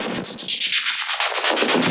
RI_ArpegiFex_125-03.wav